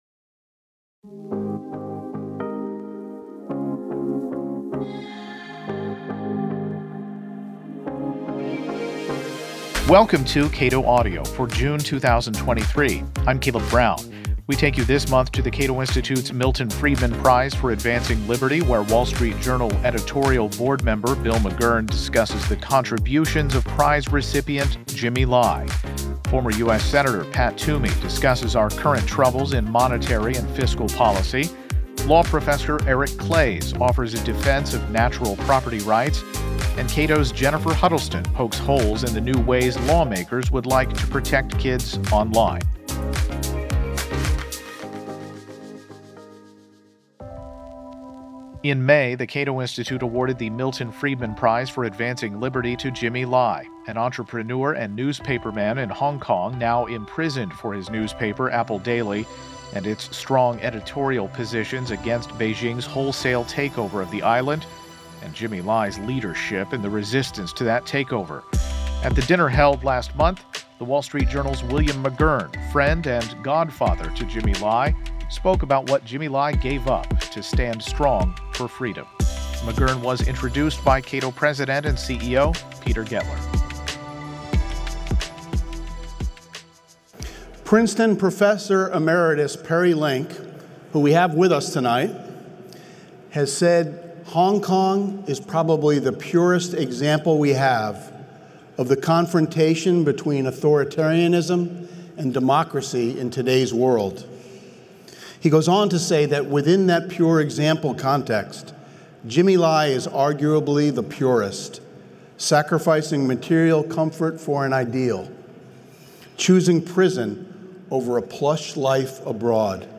Friedman Prize keynote address by William McGurn